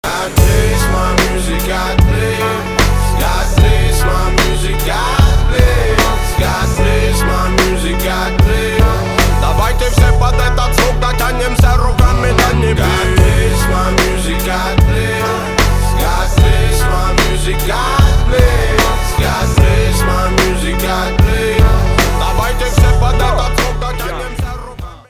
мужской вокал
Хип-хоп
русский рэп
спокойные
растаманские